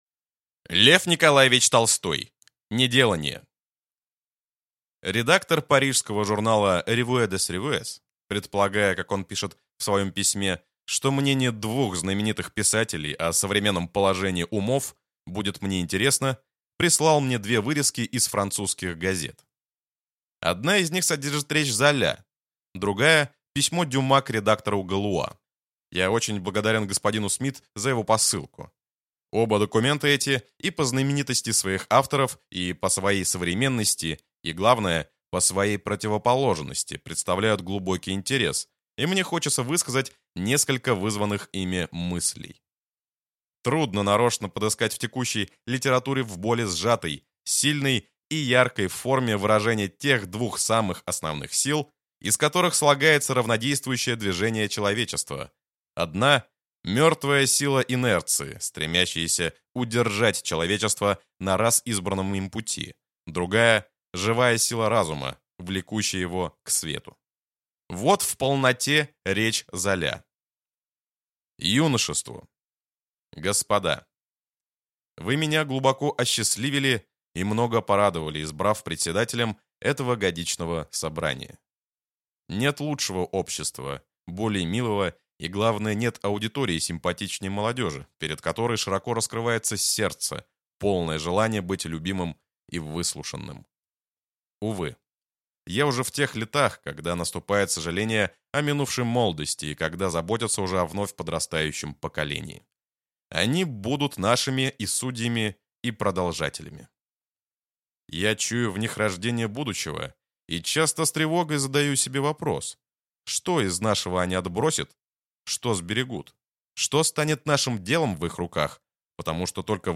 Аудиокнига Неделание | Библиотека аудиокниг